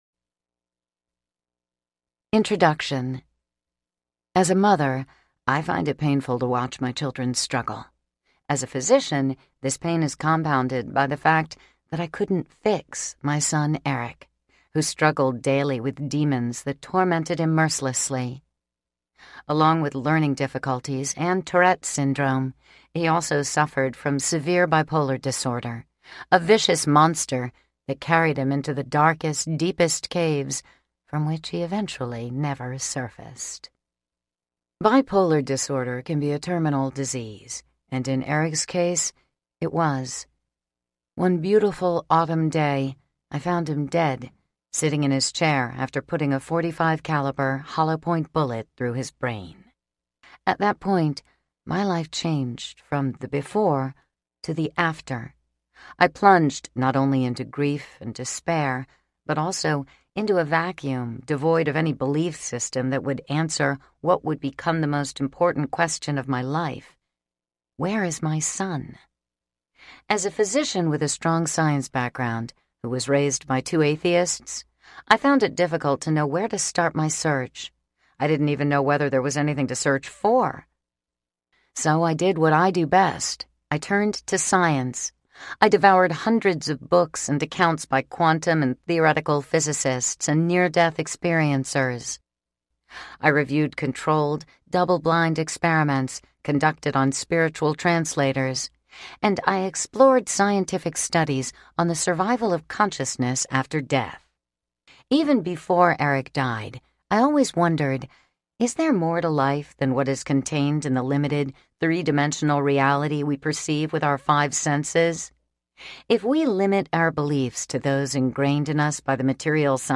My Son and the Afterlife Audiobook
Narrator
8.8 Hrs. – Unabridged